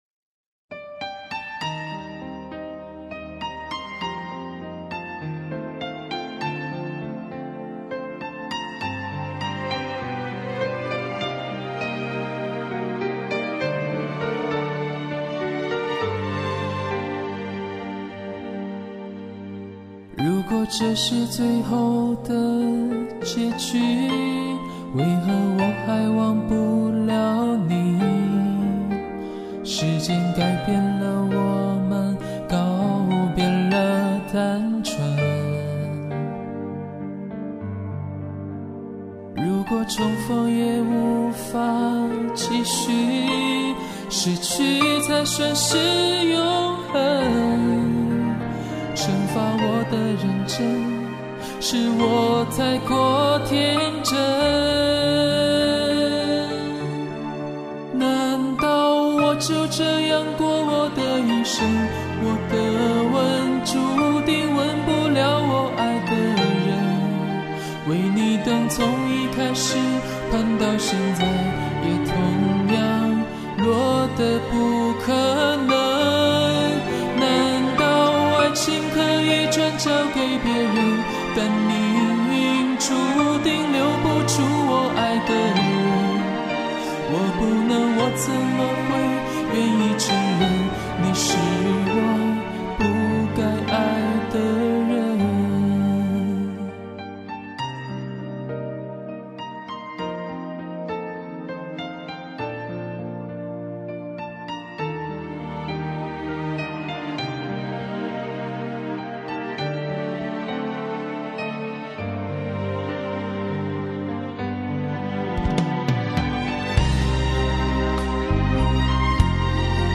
我觉得还可以唱的再温柔一些